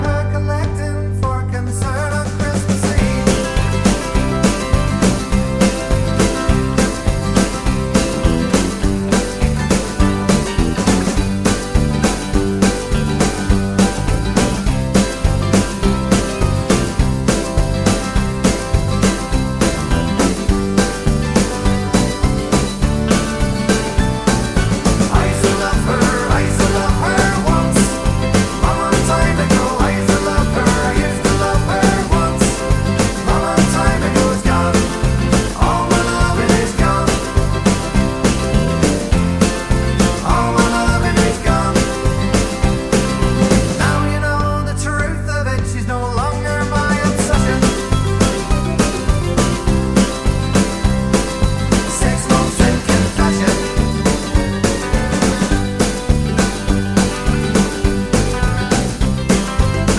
no Backing Vocals Irish 2:47 Buy £1.50